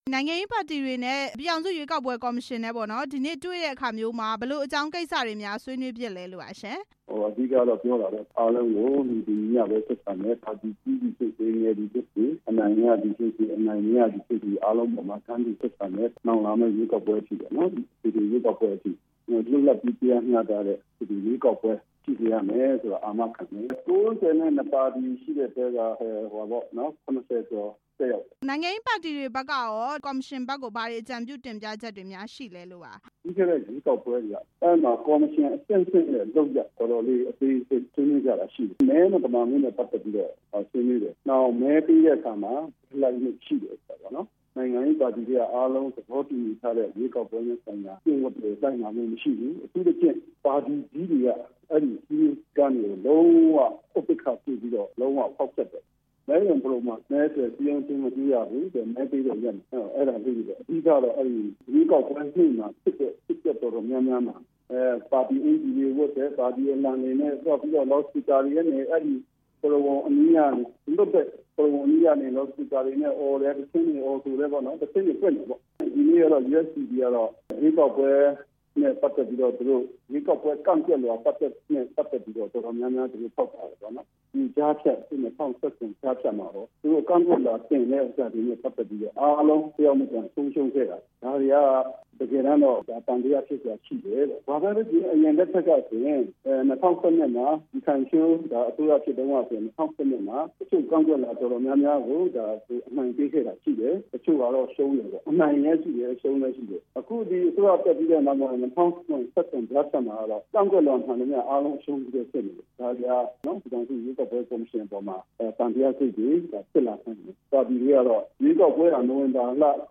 ကြားဖြတ်ရွေးကောက်ပွဲ ရွှေ့ဆိုင်းပေးဖို့တောင်းဆိုတဲ့ အကြောင်း မေးမြန်းချက်